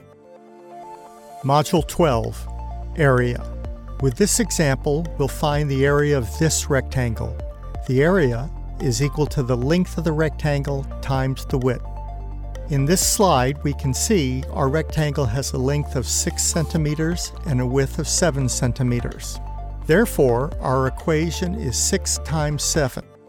American Male Tenor Voice Artist
englisch (us)
Sprechprobe: eLearning (Muttersprache):
Geometry Module 12 Area DEMO.mp3